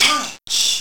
otherPunch.wav